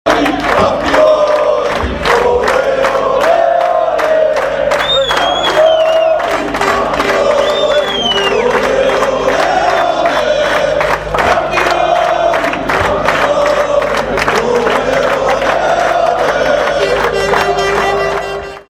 La 24 de ore distanță de la bucuria promovării în Liga I, consumată la Pitești, UTA a sărbătorit marea reușită a sezonului și în orașul ei.
Campionii-campionii.mp3